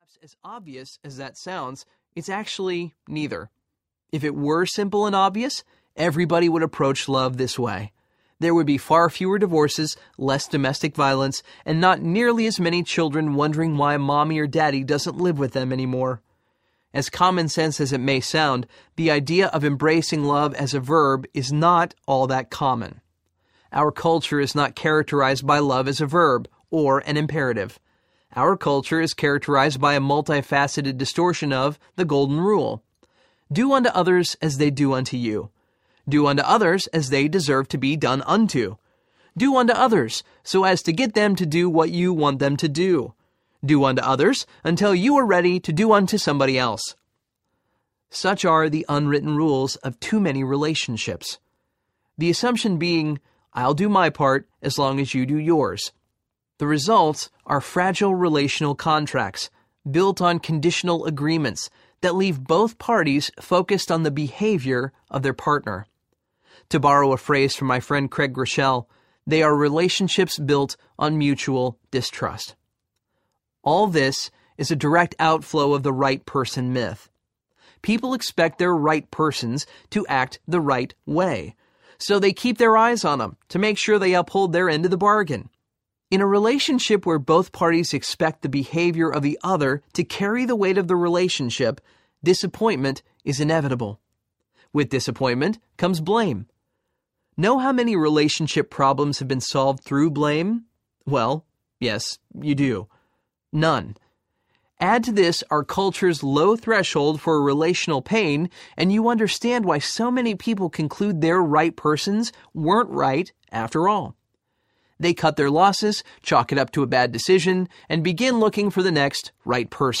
The New Rules for Love, Sex and Dating Audiobook
4.52 Hrs. – Unabridged